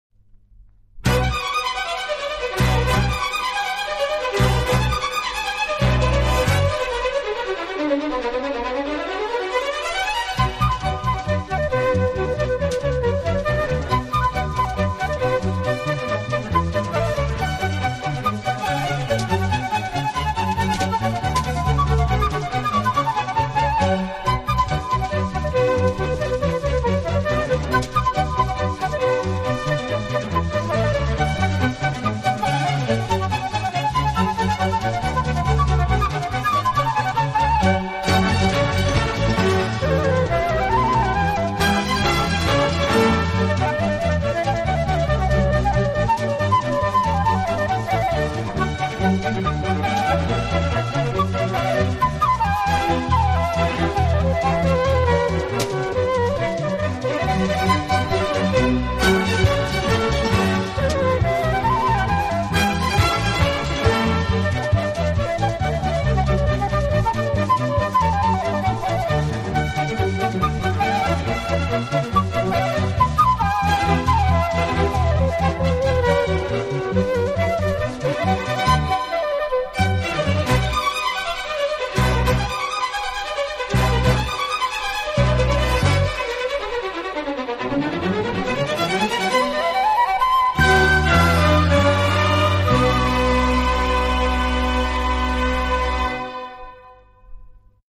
轻音乐
体，曲风浪漫、优雅，令人聆听時如感轻风拂面，丝丝柔情触动心扉，充分领略